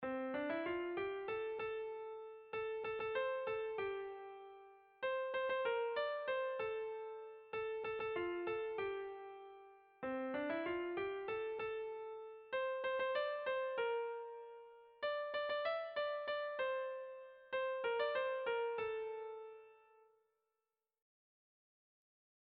Erlijiozkoa
Zortziko txikia (hg) / Lau puntuko txikia (ip)
A1BA2D